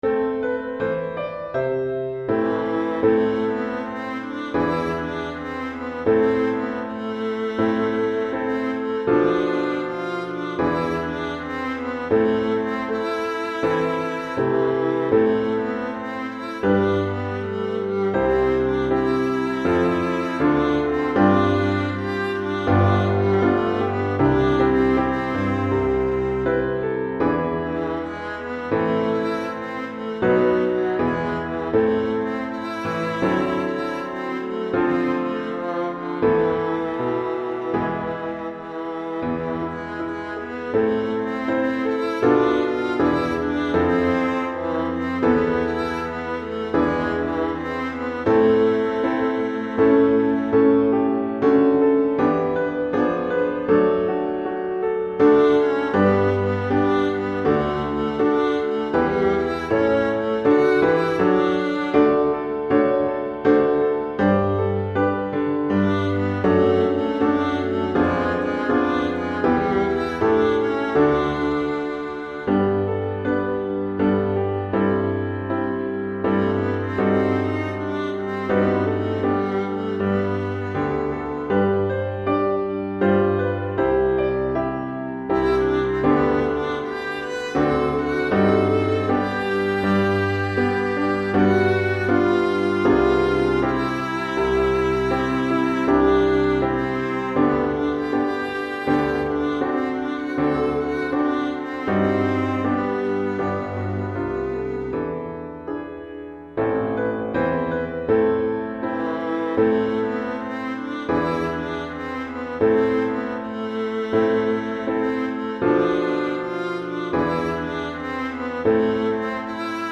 Alto et Piano